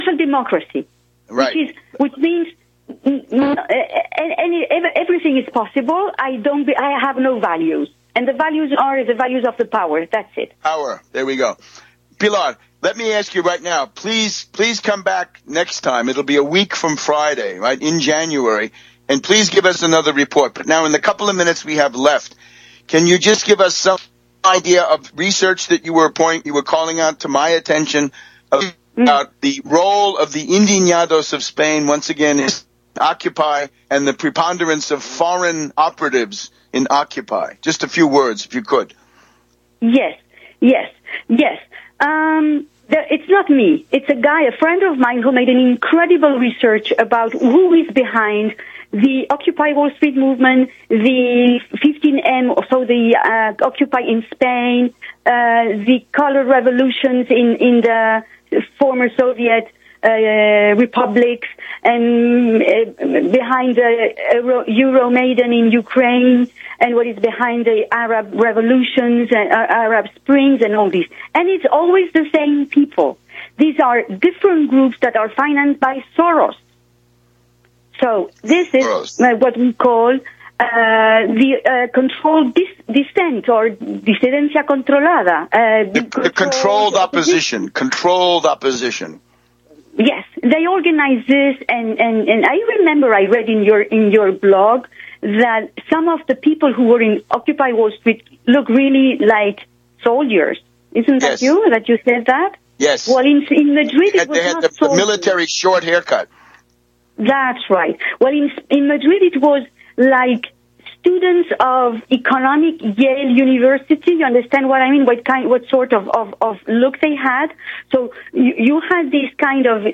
a reading